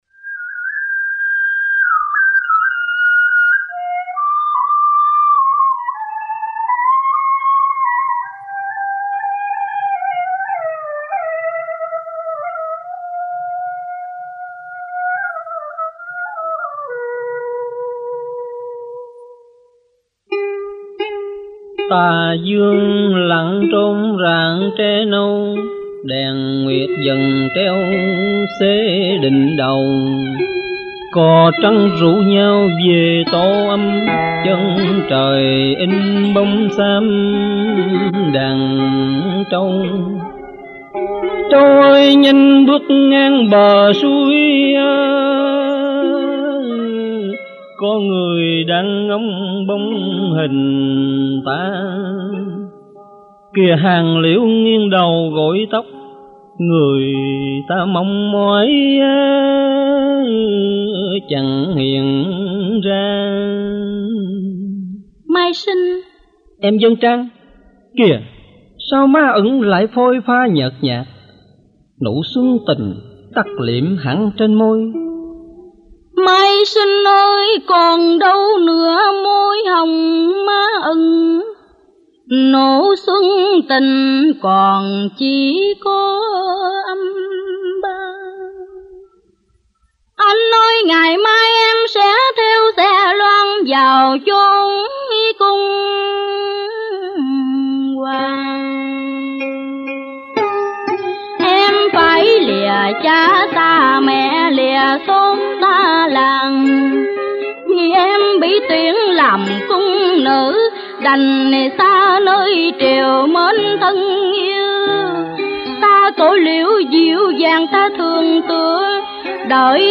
Thể loại: Cải Lương https